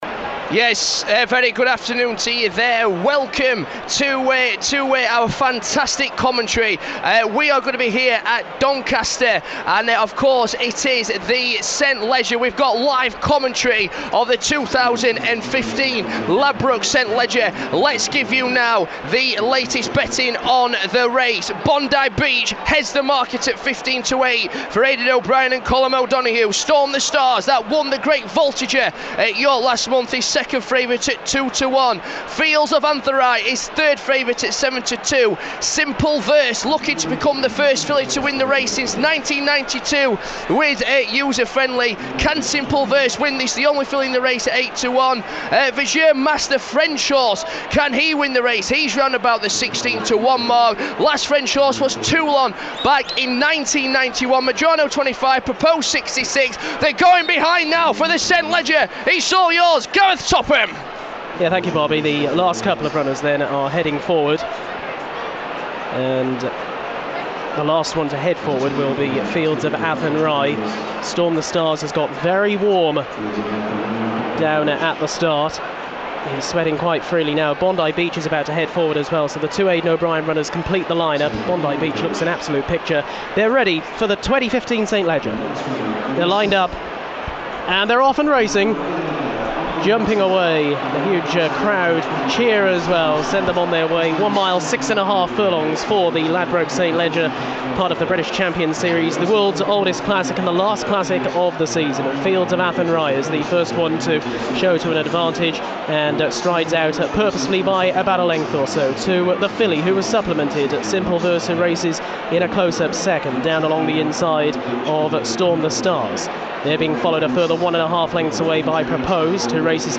St Leger Race Commentary & Analysis